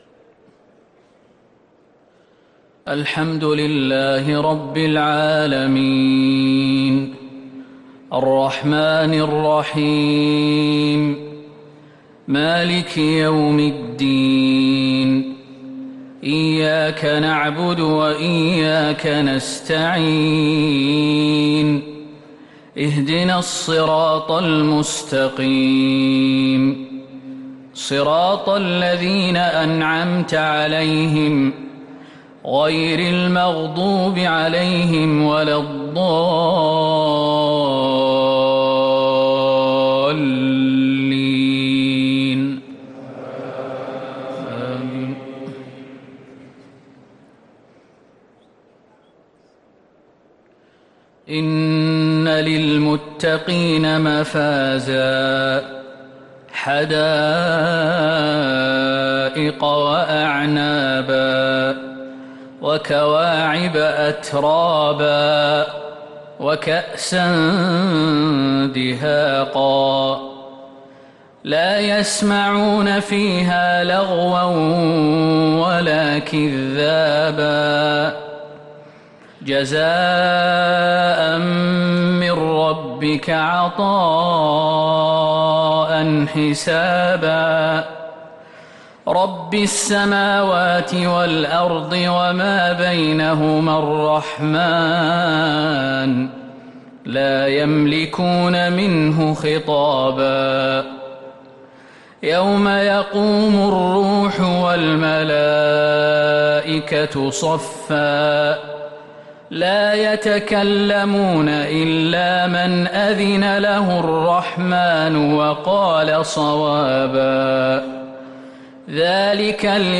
مغرب الأربعاء 5 محرم 1444هـ من سورتي النبأ و الإنفطار | Maghrib prayer from Surah AnNaba & AlInfitar 3-8-2022 > 1444 🕌 > الفروض - تلاوات الحرمين